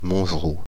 Montgeroult (French pronunciation: [mɔ̃ʒ(ə)ʁu]
Fr-Paris--Montgeroult.ogg.mp3